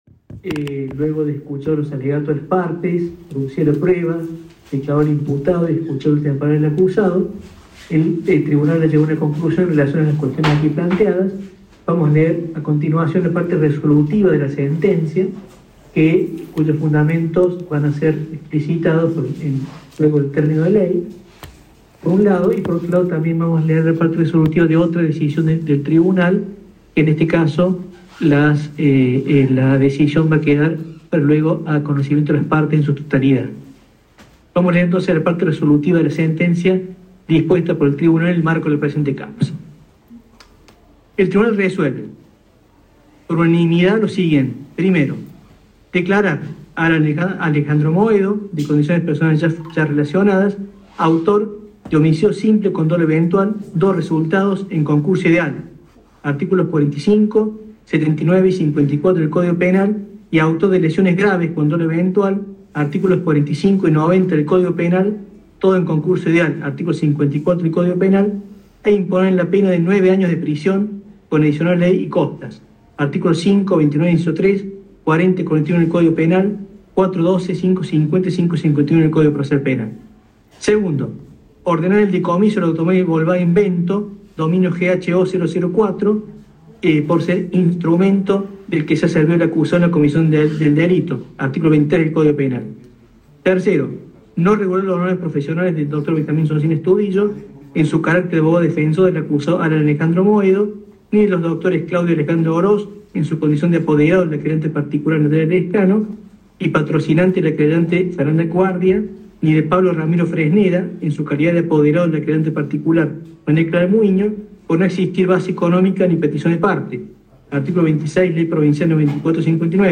Lectura del fallo en el caso de la tragedia en Circunvalación
Todo esto se conjugó este viernes temprano en la sala de audiencias de la Cámara 9ª del Crimen, donde se escuchó un falló histórico para Córdoba.